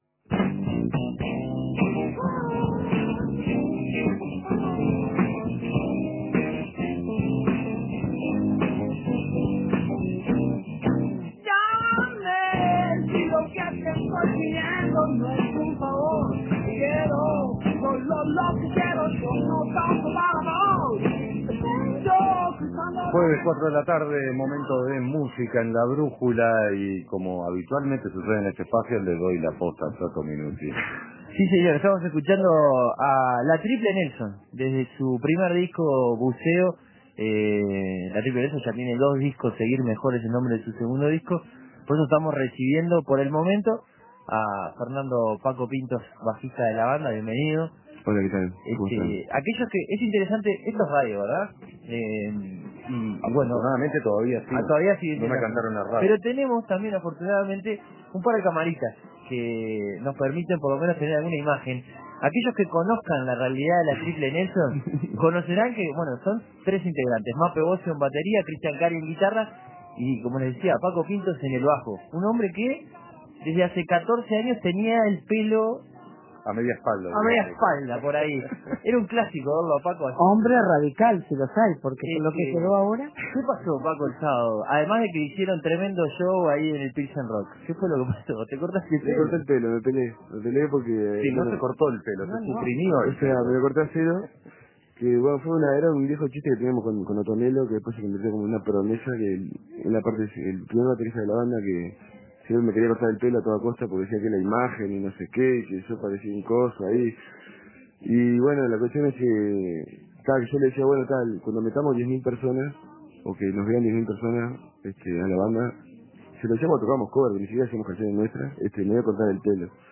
bajo
guitarra y voz
con su música inundaron el aire de rock y blues